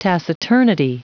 Prononciation du mot taciturnity en anglais (fichier audio)
Prononciation du mot : taciturnity